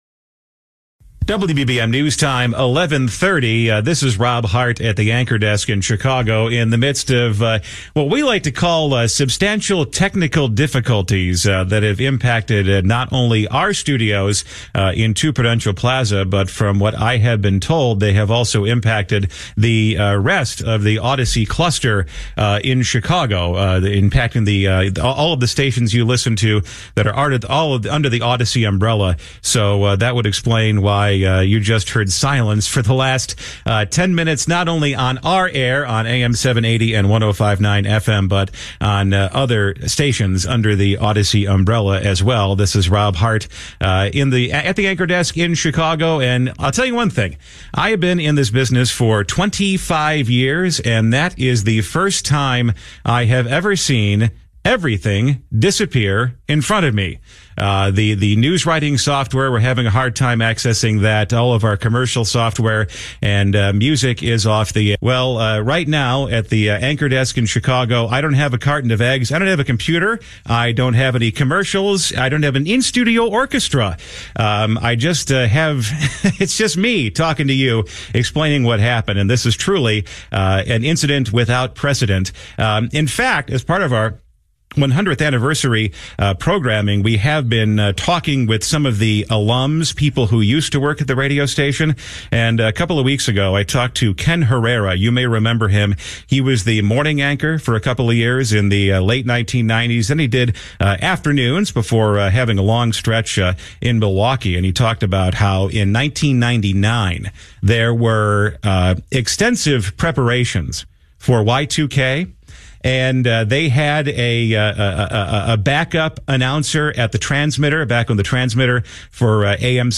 In the 11 o'clock hour on Friday morning, an outage in the newsroom led to WBBM's signal coming off the air and news gathering systems going down. WBBM NEWSRADIO